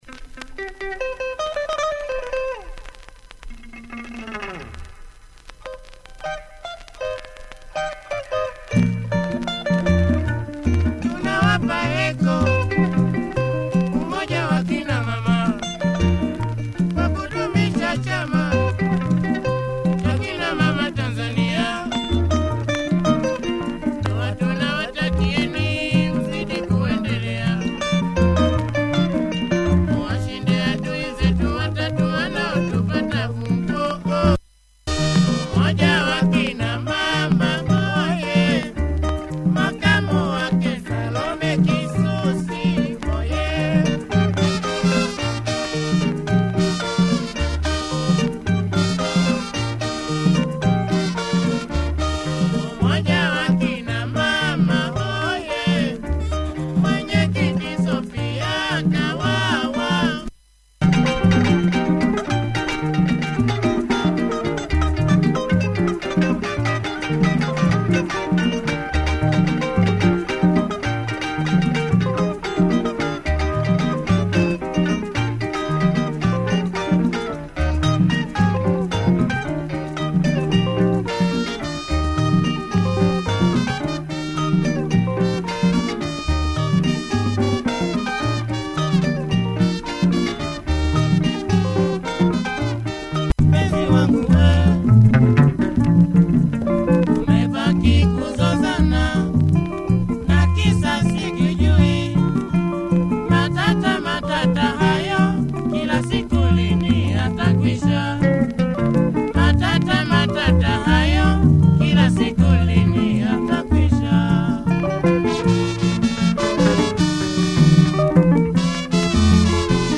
guitar
has some wear but plays through fine.